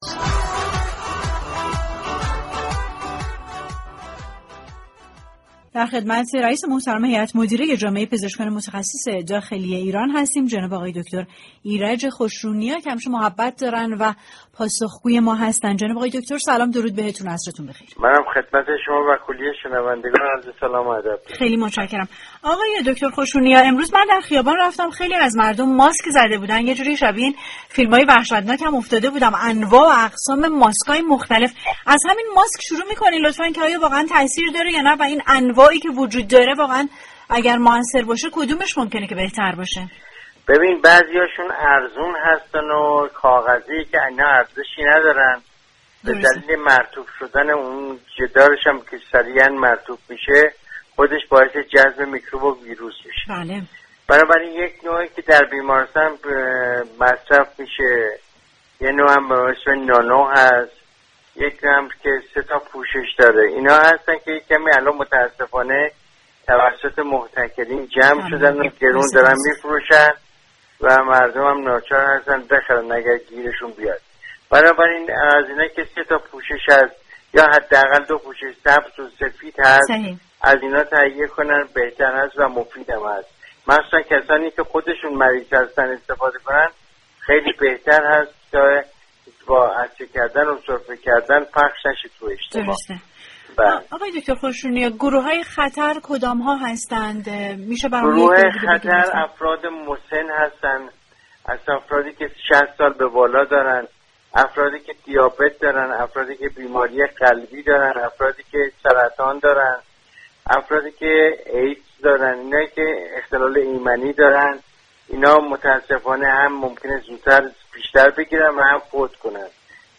در گفتگویی تلفنی